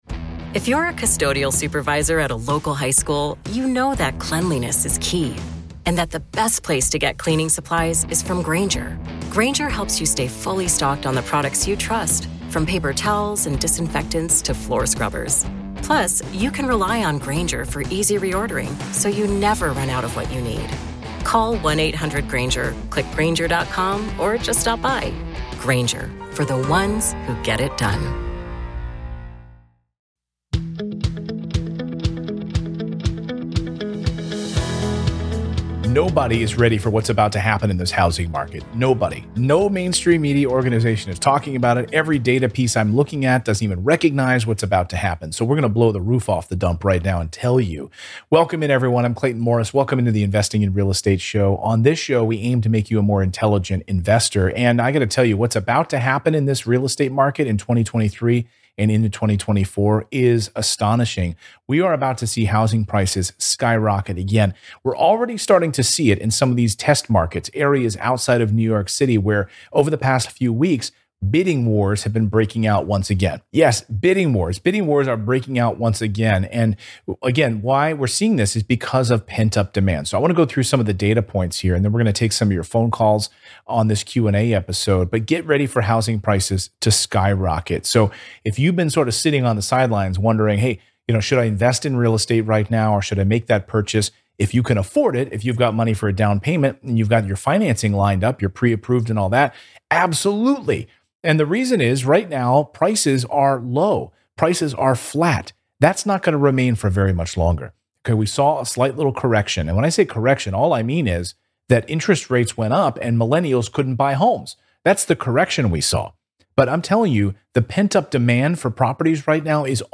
Today's first caller asked about using annuities to begin investing in real estate.